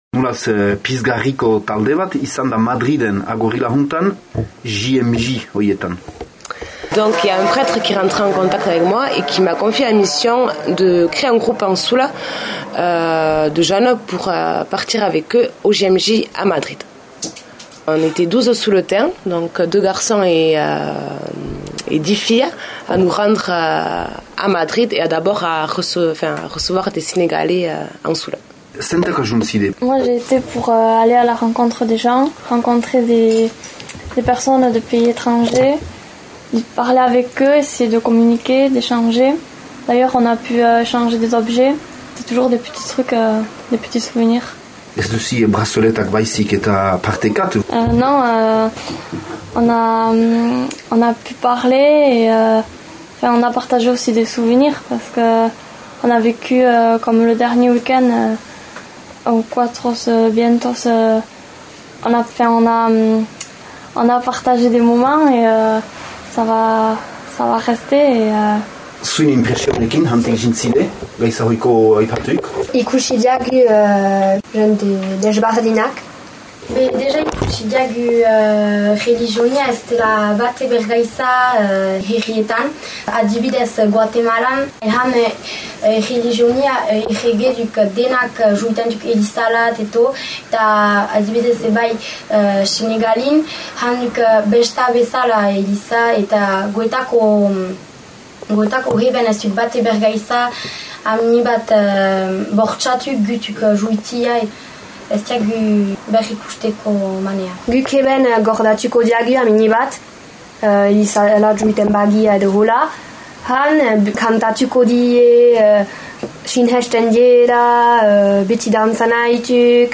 Entzün Xiberotar zonbaiten lekükotarzünak Mündüko Gazteen Egünaldietarik landa :